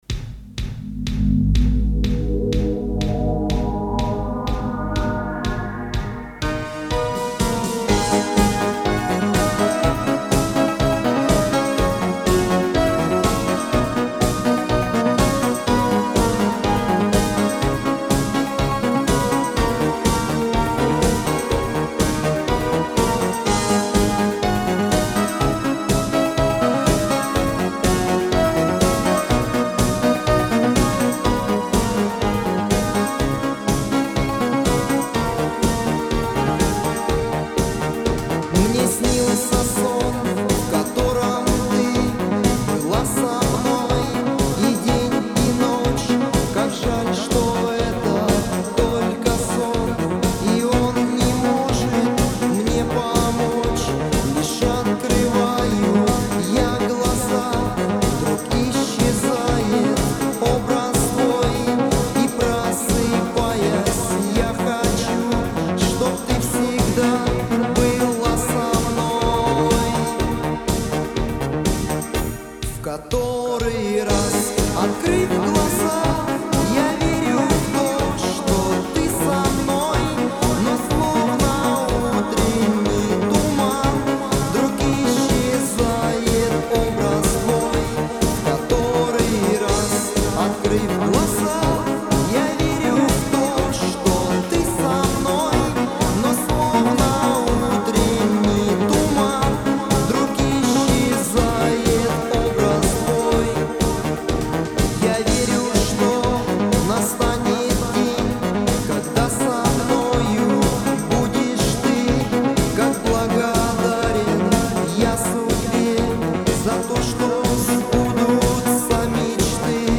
Партия в проигрыше почти в начале.